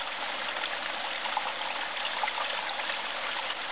1 channel
Fountain2.mp3